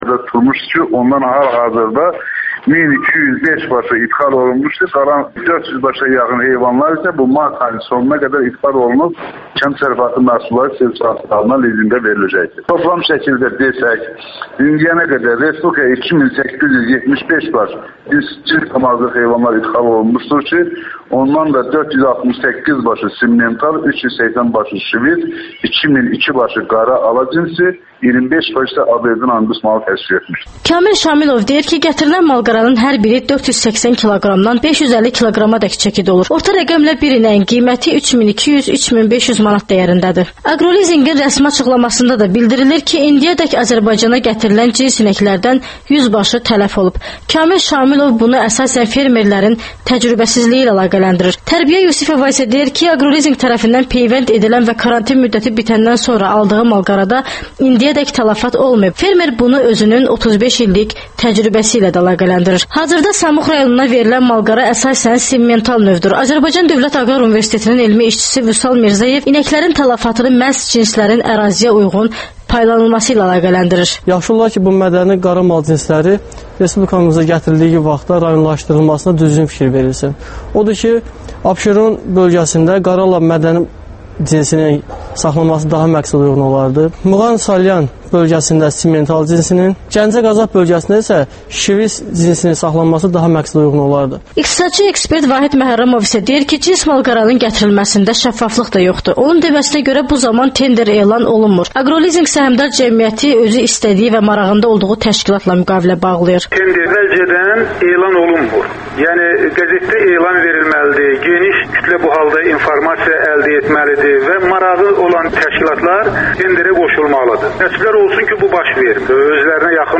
Rayonlardan xüsusi reportajlar